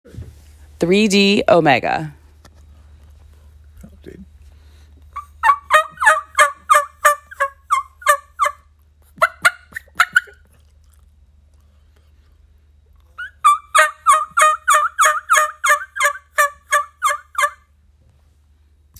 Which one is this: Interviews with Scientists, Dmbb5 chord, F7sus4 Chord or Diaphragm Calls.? Diaphragm Calls.